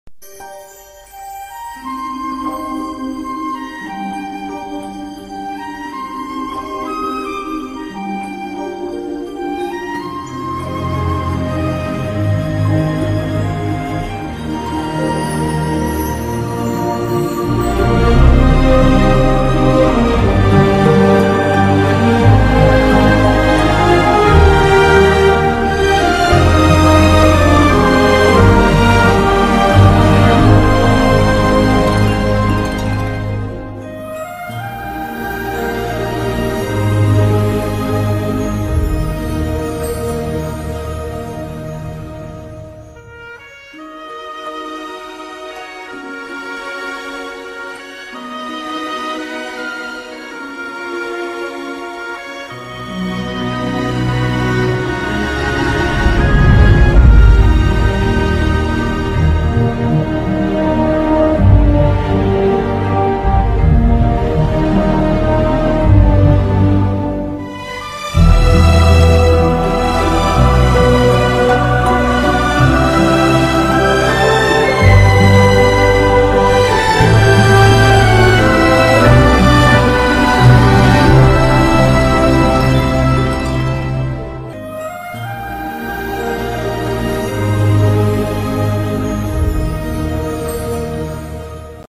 Звук заставки мультфильма